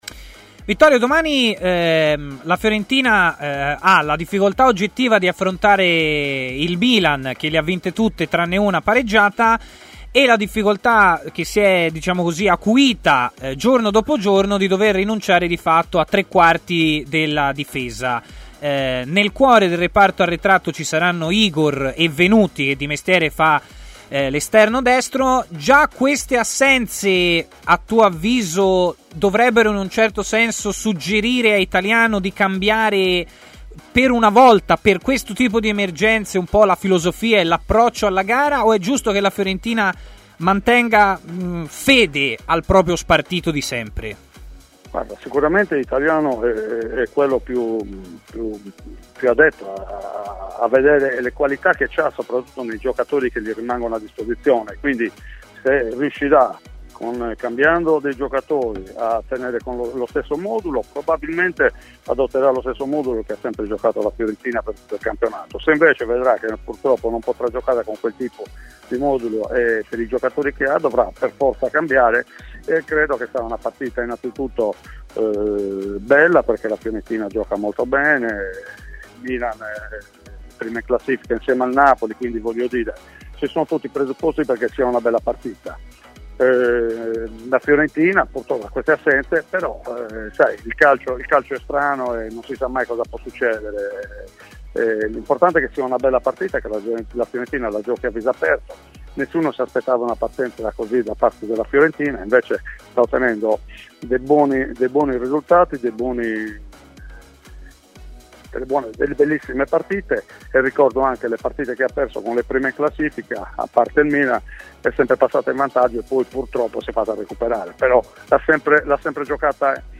L'allenatore ed ex calciatore Vittorio Pusceddu è intervenuto a Stadio Aperto, trasmissione pomeridiana di TMW Radio, parlando di vari temi, a cominciare dal momento che sta vivendo la Fiorentina, alla vigilia della delicata sfida contro il Milan.